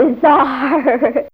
Index of /m8-backup/M8/Samples/Fairlight CMI/IIe/27Effects4
Bizarre.wav